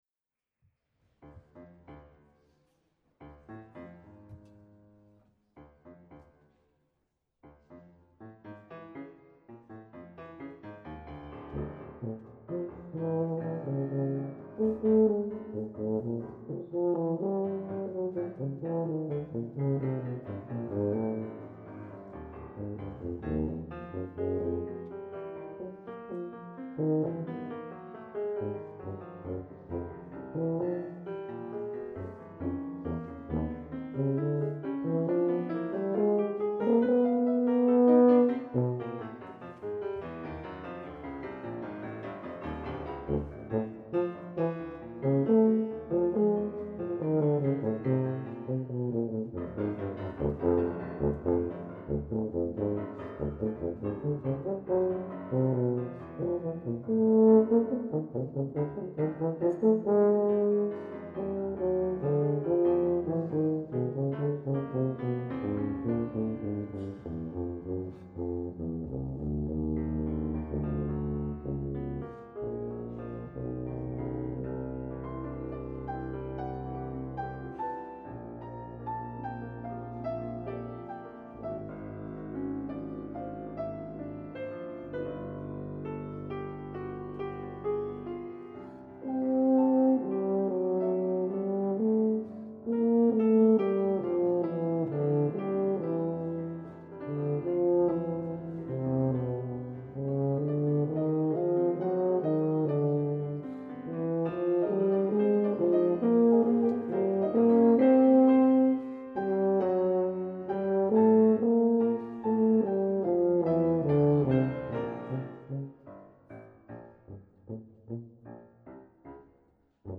Thomas Benjamin—Sonata for Tuba and Piano
tuba
piano
I – Allegro moderato